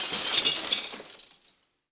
glass_smashable_debris_fall_02.wav